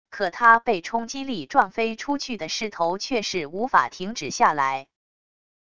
可他被冲击力撞飞出去的势头却是无法停止下来wav音频生成系统WAV Audio Player